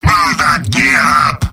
Giant Robot lines from MvM. This is an audio clip from the game Team Fortress 2 .